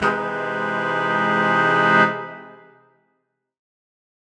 Index of /musicradar/undercover-samples/Horn Swells/C
UC_HornSwell_Cmin6maj7.wav